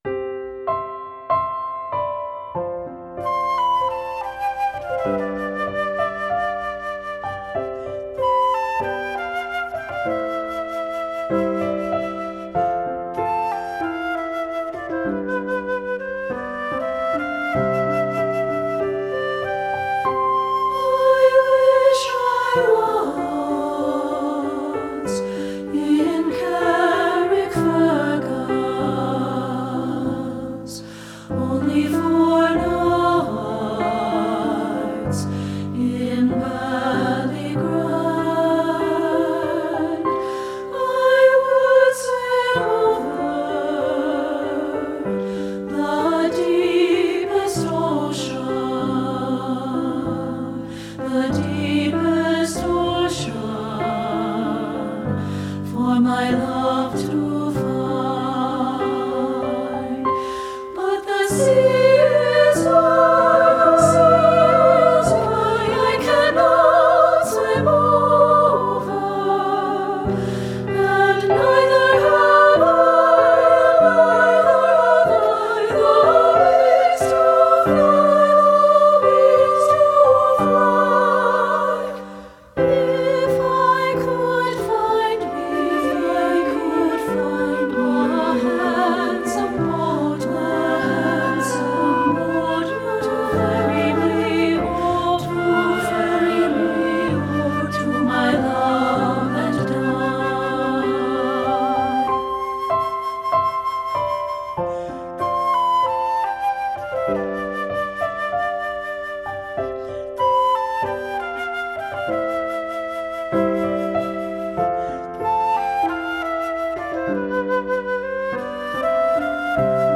• Soprano
• Alto
• Piano
• Flute
Studio Recording
Ensemble: Treble Chorus
Key: C major
Tempo: Espressivo (q = 96)
Accompanied: Accompanied Chorus